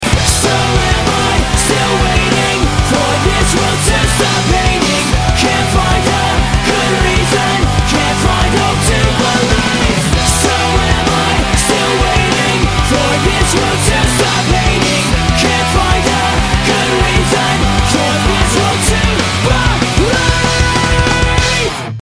Главная » Файлы » Рок